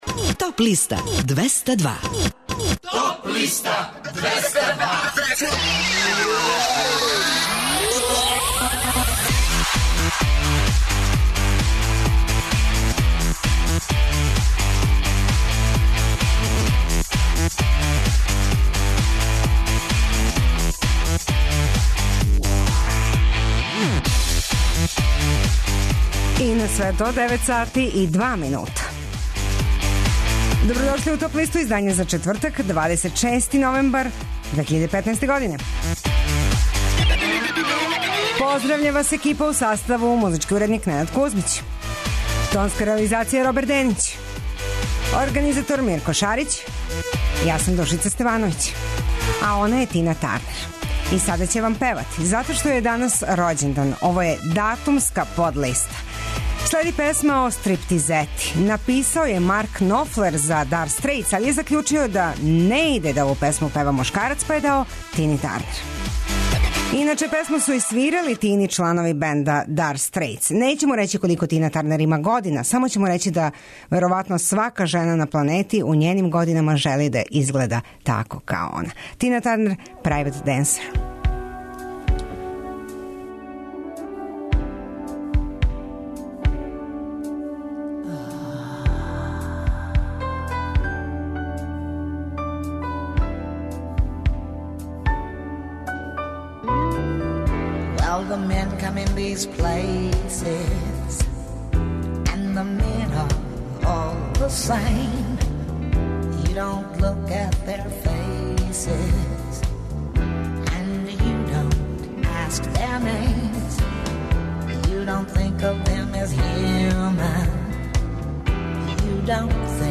преузми : 24.84 MB Топ листа Autor: Београд 202 Емисија садржи више различитих жанровских подлиста.